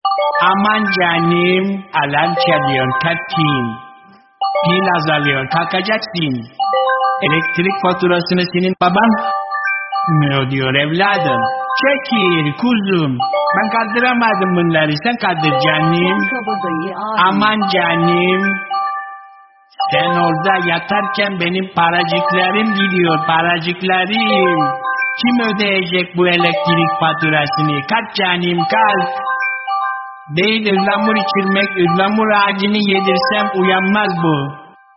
İplikçi Nedim Alarm Sesi
Kategori: Zil Sesleri